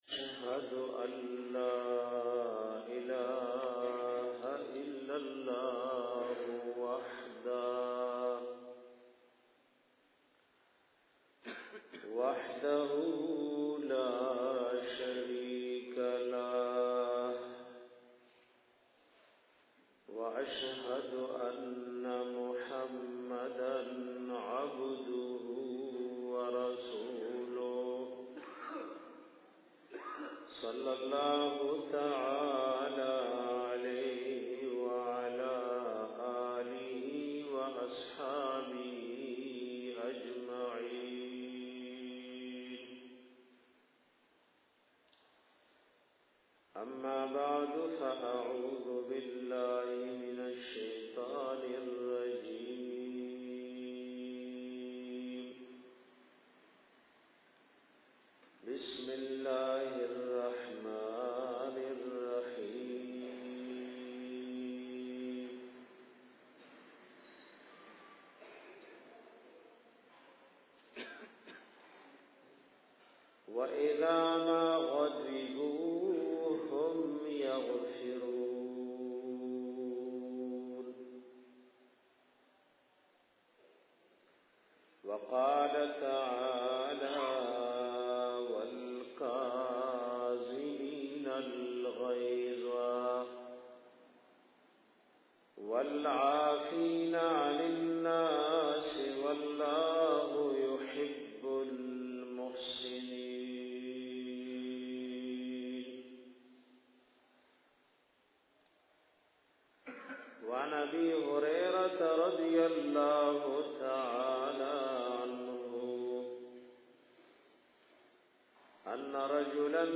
bayan pa bara da ghusa ao ghazzab ka